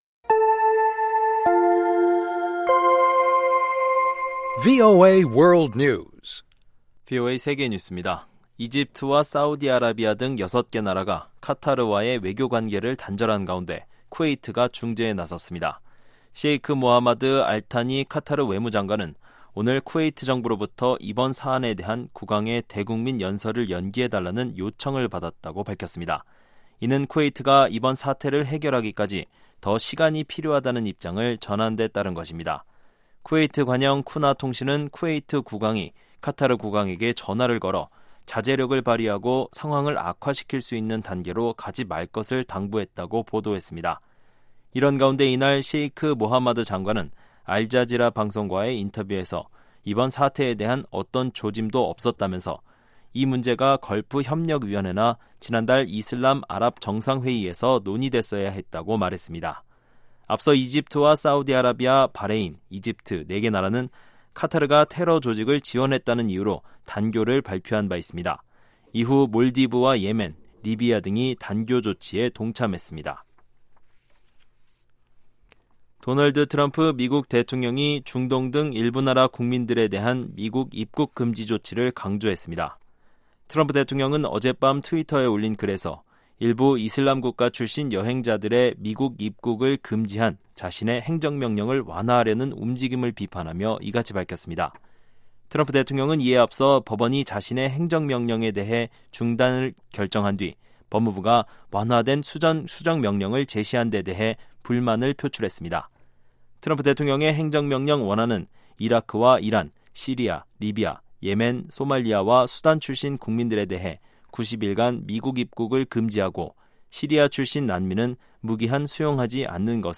VOA 한국어 방송의 간판 뉴스 프로그램 '뉴스 투데이' 3부입니다.